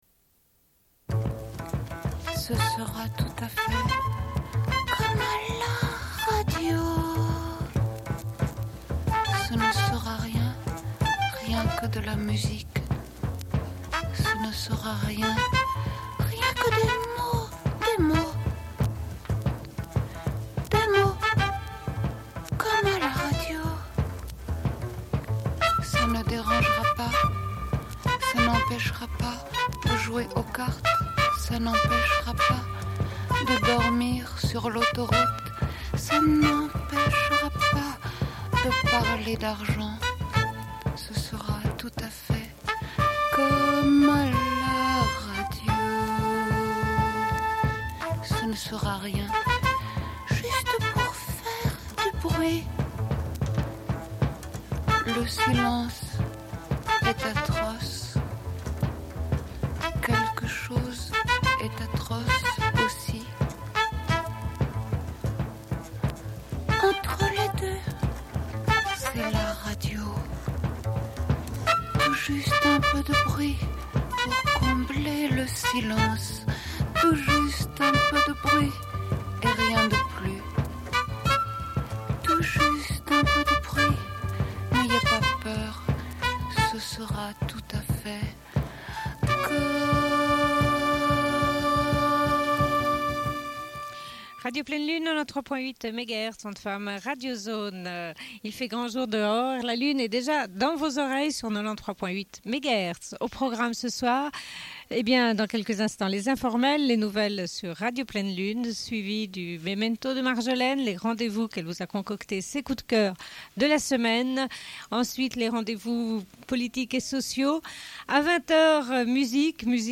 Bulletin d'information de Radio Pleine Lune du 24.03.1993 - Archives contestataires
Une cassette audio, face B29:10